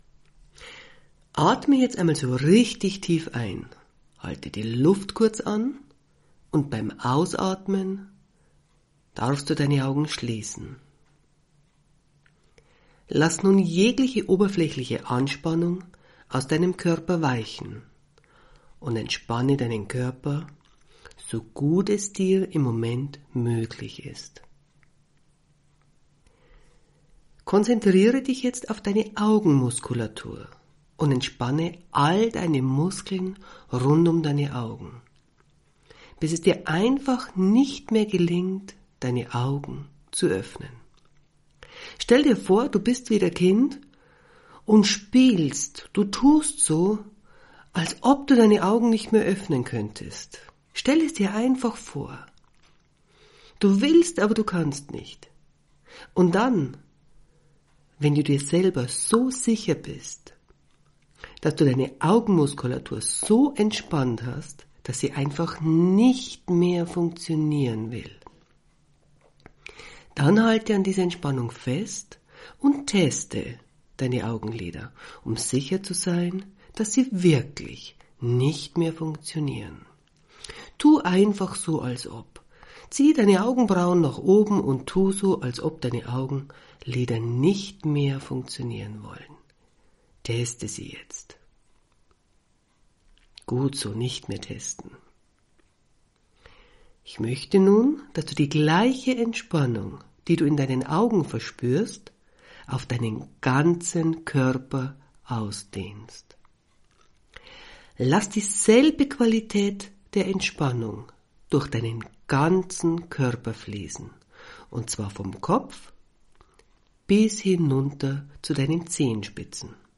hypnose-schlaf-heilung-gute-gefuehle.mp3